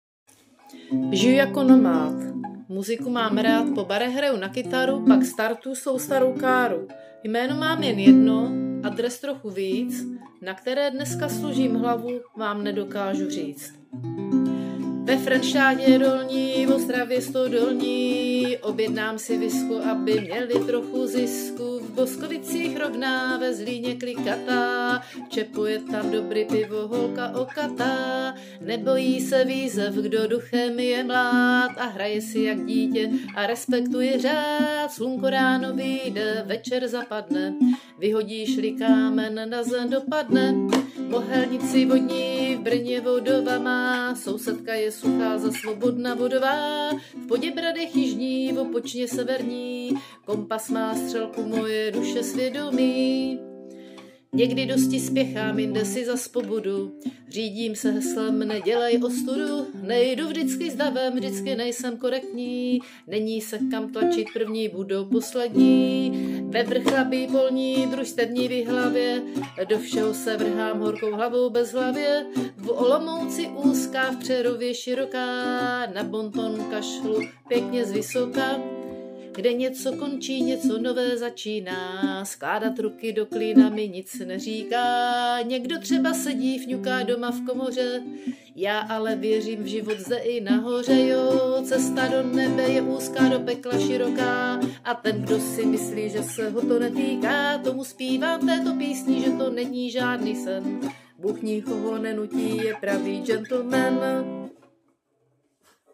Anotace: píseň potulneho muzikanta